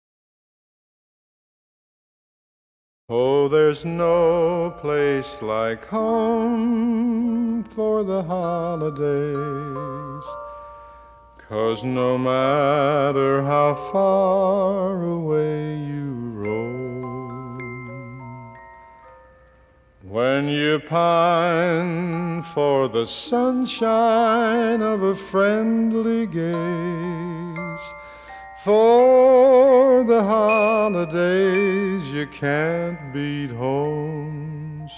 # Christmas: Classic